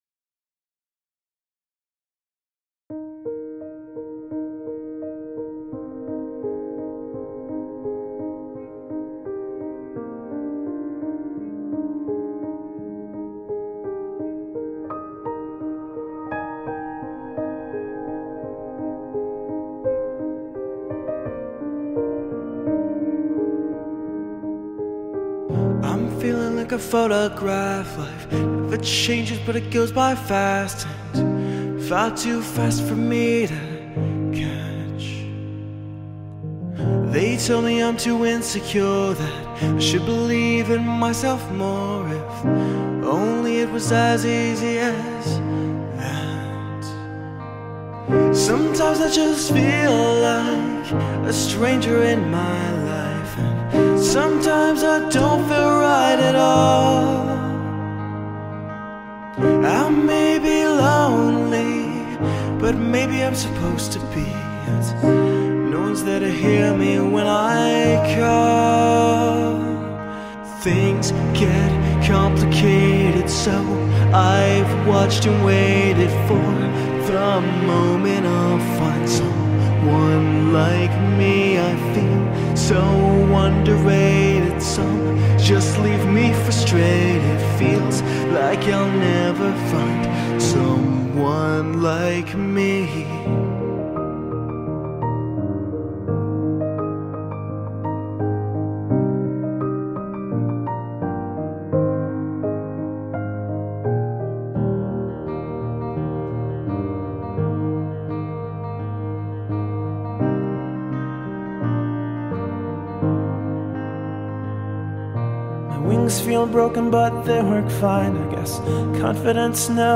we have this heartfelt, emotional ballad!